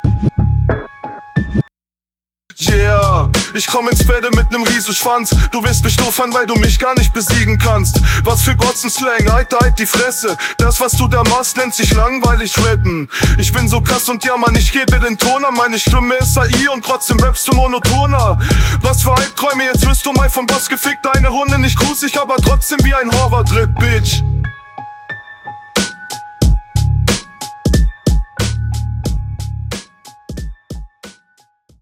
Jede Runde von euch beiden leidet unter Mix und Text irgendwie.